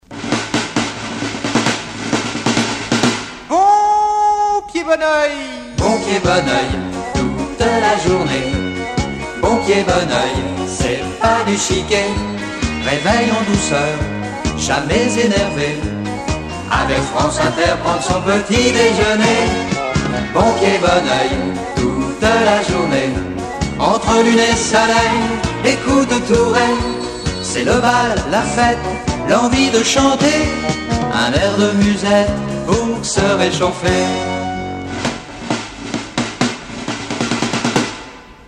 l’indicatif de l’émission